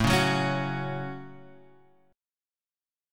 A6 Chord